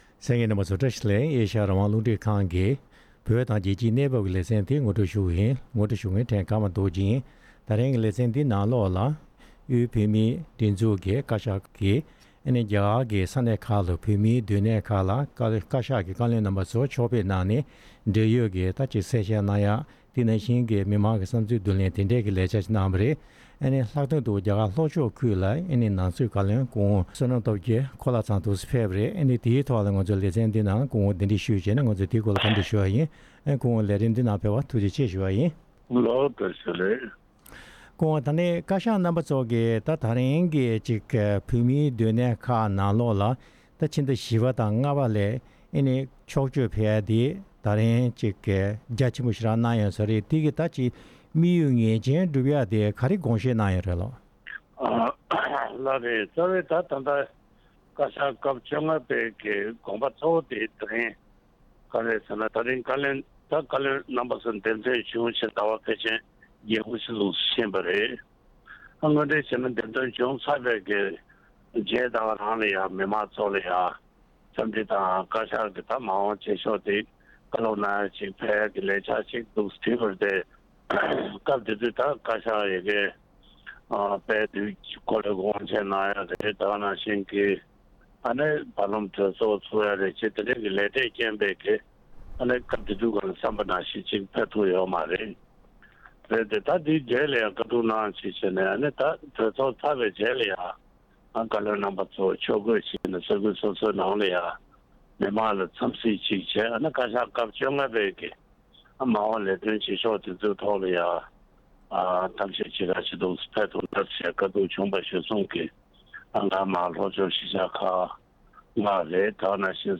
བགློ་གླེང་གནང་བར་གསན་རོགས་ཞུ༎